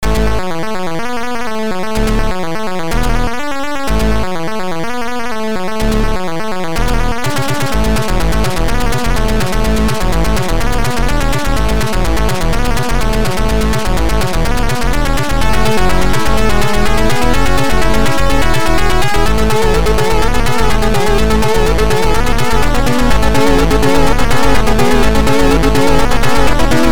( .mp3 ) < prev next > Abyss Highest Experience Module | 1997-03-09 | 3.0 KB | 2 channels | 44,100 sample rate | 26 seconds Transcription: Music Title I Stole Deve! Type AHX v2 Tracker Abyss' Highest Experience v1.00-1.27